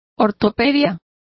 Complete with pronunciation of the translation of orthopedics.